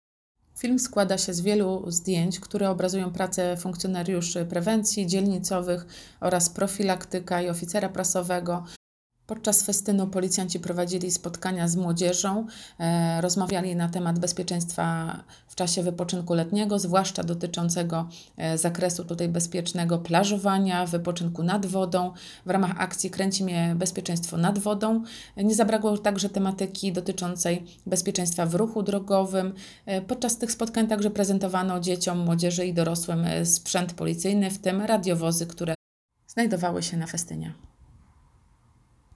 Nagranie audio Wejherowo_festyn_na_przystani_kajakowej_nad_jeziorem.m4a